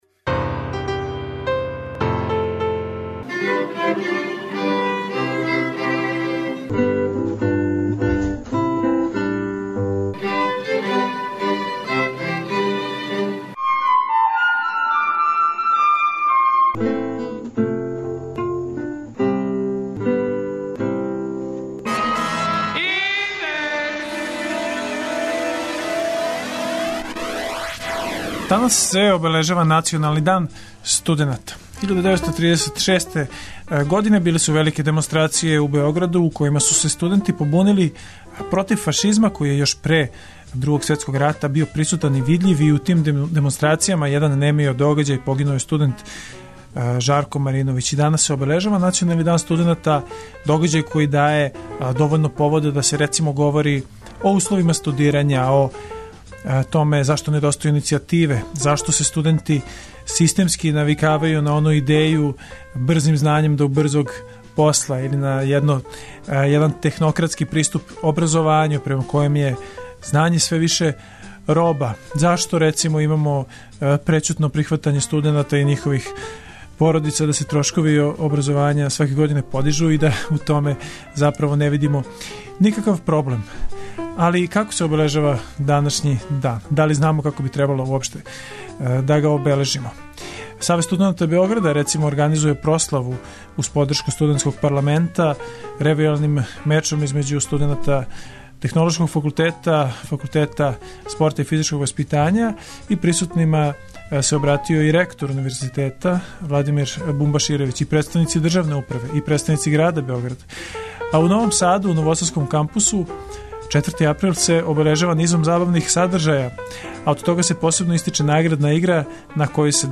Разговарамо са професорима универзитета и студентима о томе како побољшати положај студената у друштву, који је све маргиналнији.